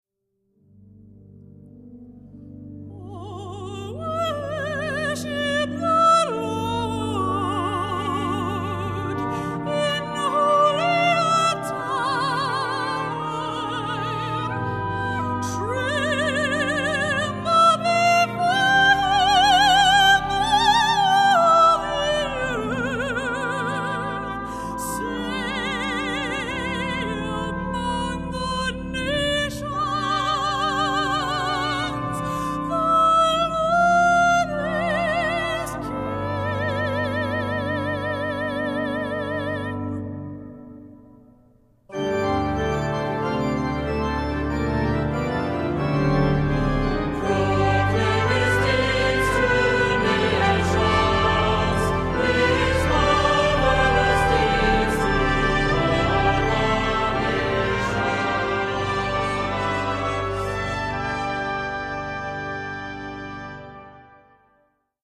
Voicing: Cantor; Assembly